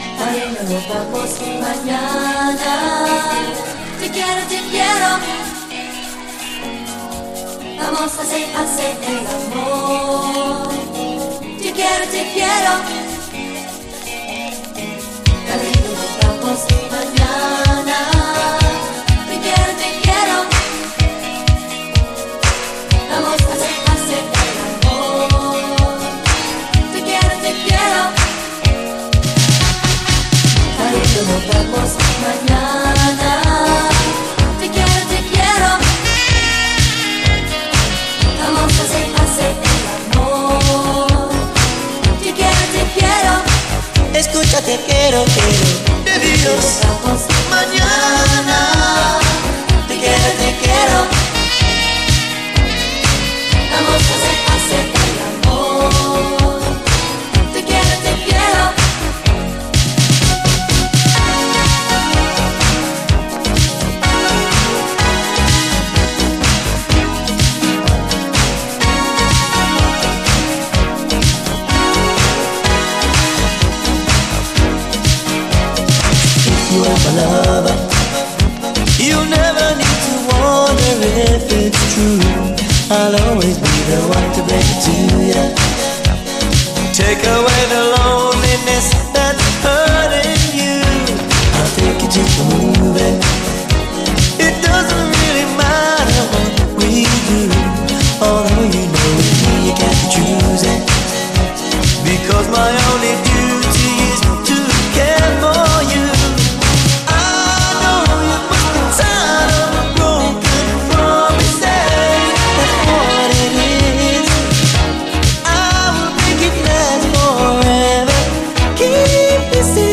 こちらはプロモ・ホワイト盤で、通常盤よりもビートが太く強調された別ミックスとなっています。